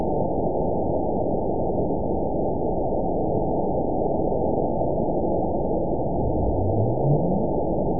event 920436 date 03/25/24 time 00:28:54 GMT (1 year, 1 month ago) score 9.67 location TSS-AB02 detected by nrw target species NRW annotations +NRW Spectrogram: Frequency (kHz) vs. Time (s) audio not available .wav